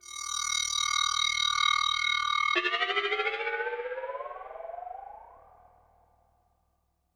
synthFX03.wav